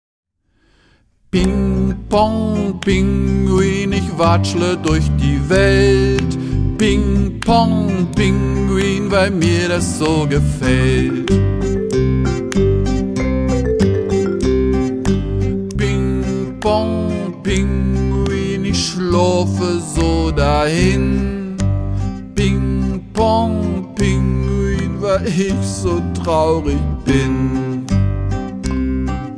Spiel- und Bewegungslieder